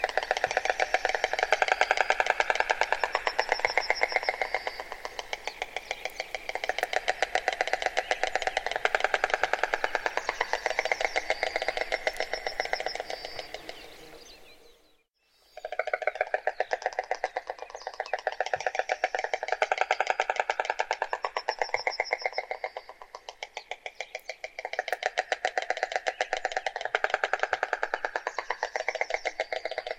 Cigogne blanche - Mes zoazos
cigogne-blanche.mp3